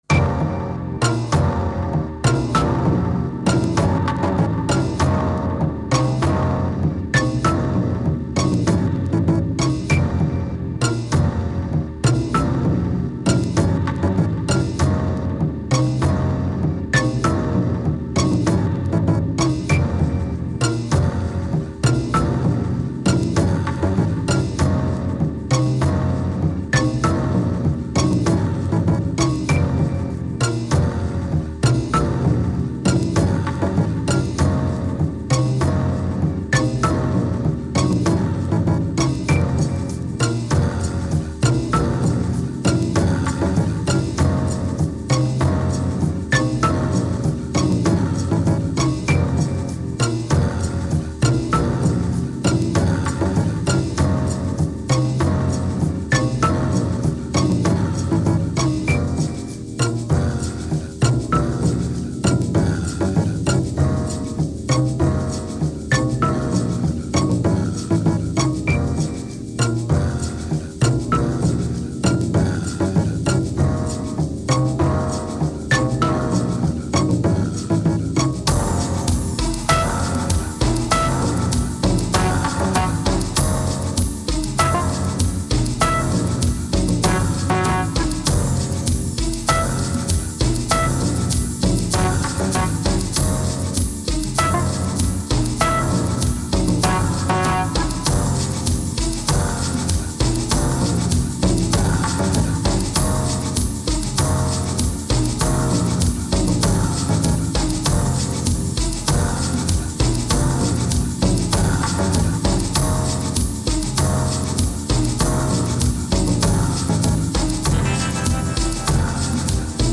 Vocals are still poor but a bit more directed.